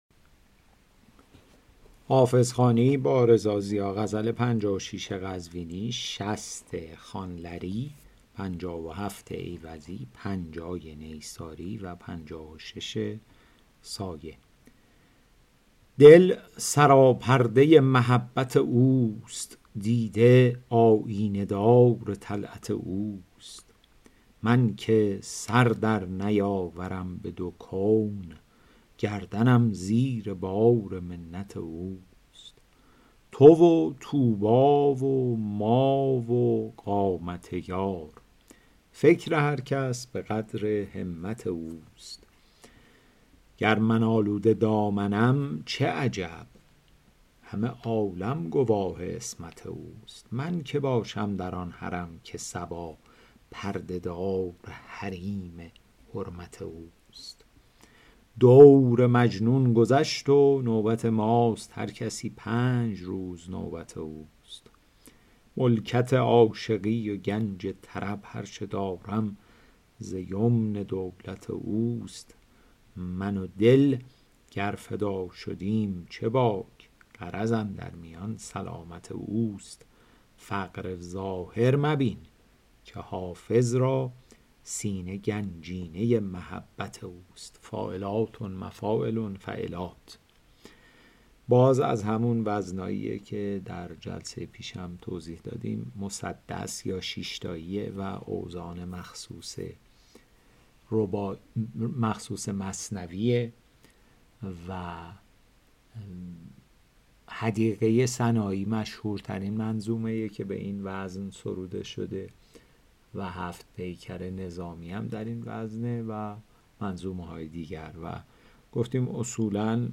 شرح صوتی غزل شمارهٔ ۵۶